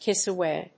アクセントkíss awáy